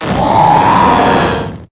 Cries
APPLETUN.ogg